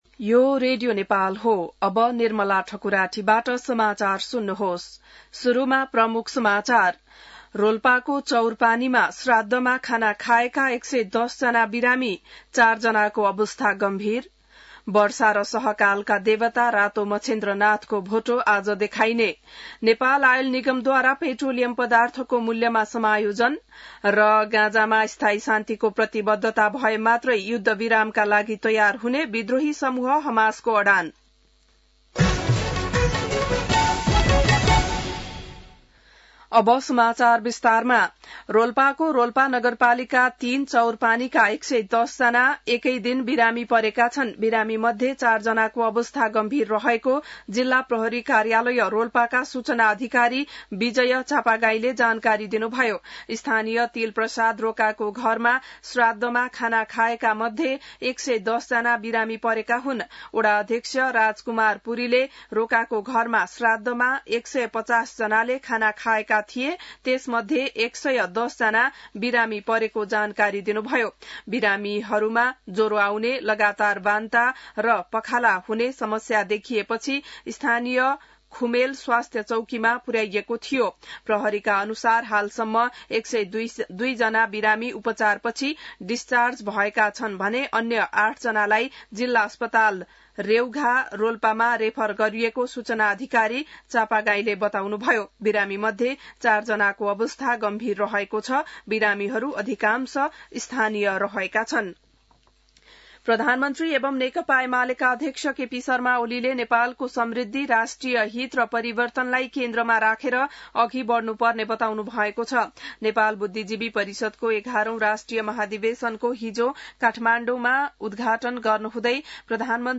बिहान ९ बजेको नेपाली समाचार : १८ जेठ , २०८२